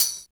85 TAMB.wav